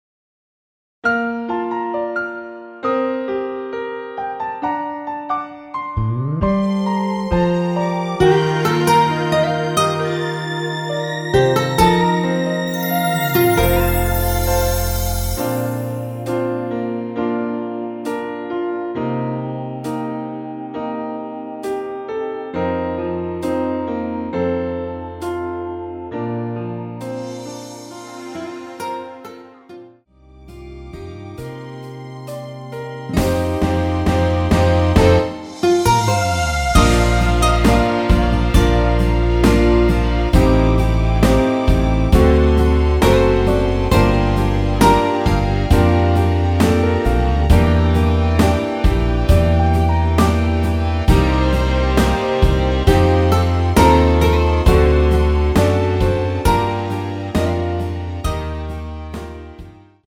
Bb
◈ 곡명 옆 (-1)은 반음 내림, (+1)은 반음 올림 입니다.
앞부분30초, 뒷부분30초씩 편집해서 올려 드리고 있습니다.
중간에 음이 끈어지고 다시 나오는 이유는
축가 MR